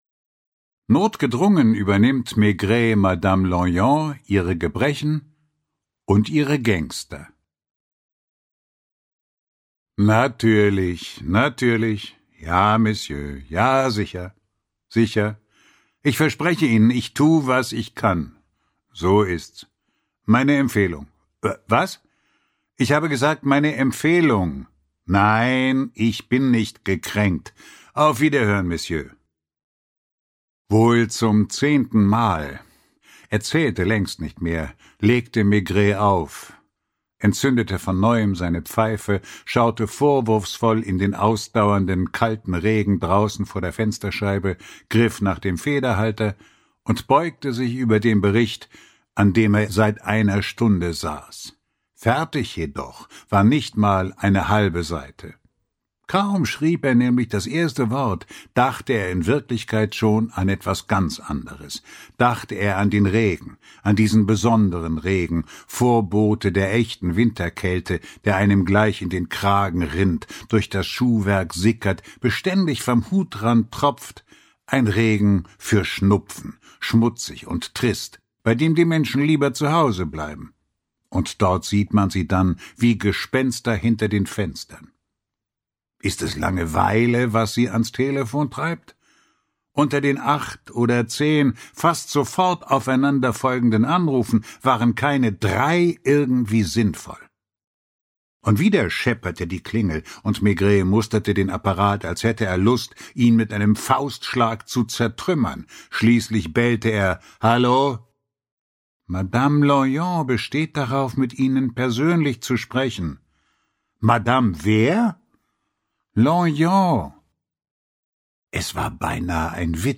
Walter Kreye (Sprecher)
Ungekürzte Lesung